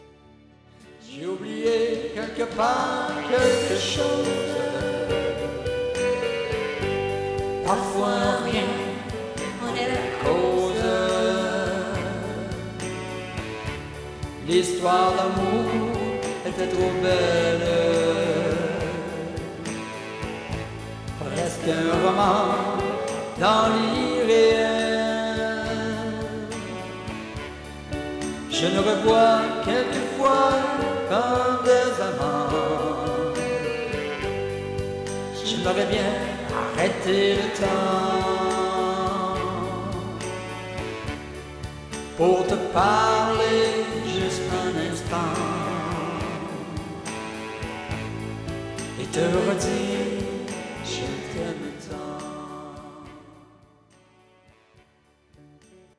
Chansons Country
Enregistrements et mixtes au Studio
La musique n'est pas d'une grande qualité.
Copié à partir d'un tape casette 4 pistes.
Guitare électrique / Guitare acc.